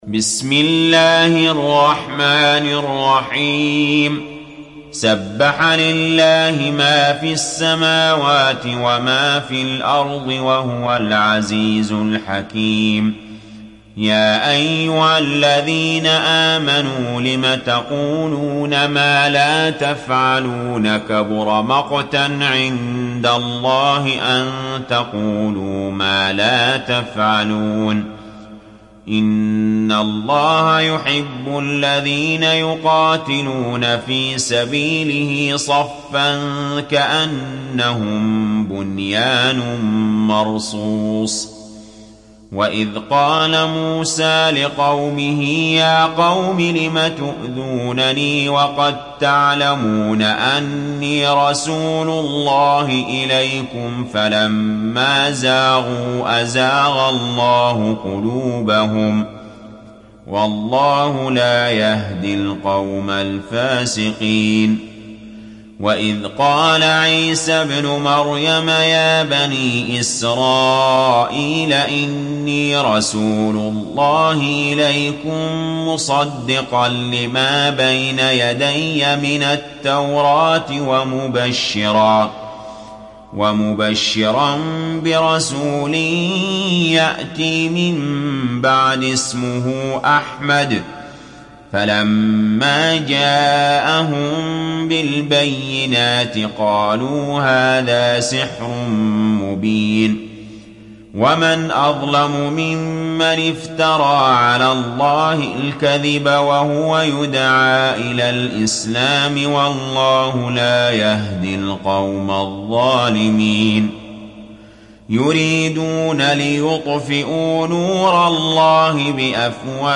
دانلود سوره الصف mp3 علي جابر روایت حفص از عاصم, قرآن را دانلود کنید و گوش کن mp3 ، لینک مستقیم کامل